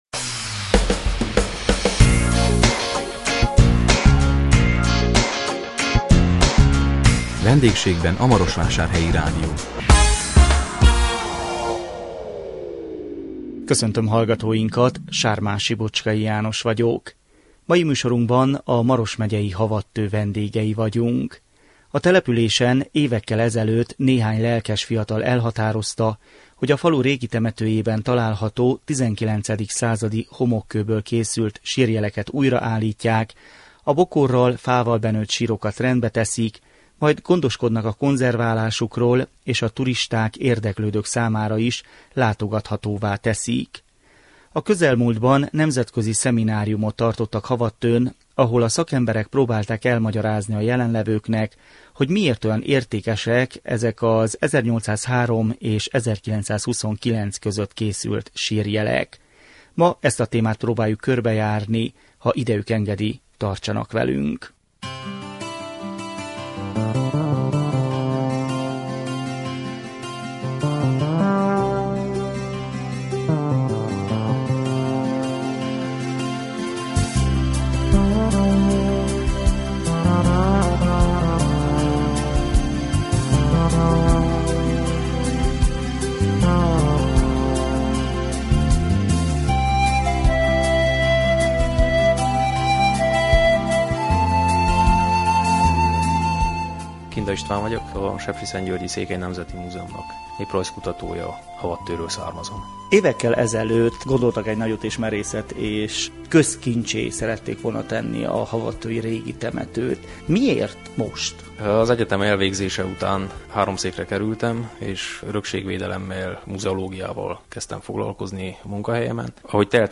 A 2016 július 28-án jelentkező Vendégségben a Marosvásárhelyi Rádió című műsorunkban a Maros megyei Havadtő vendégei voltunk. A településen évekkel ezelőtt néhány lelkes fiatal elhatározta, hogy a falu régi temetőjében található XIX. századi homokkőből készült sírjeleket újraállítják, a bokorral, fával benőtt sírokat rendbe teszik, majd gondoskodnak a konzerválásukról és a turisták, érdeklődők számára is látogathatóvá teszik.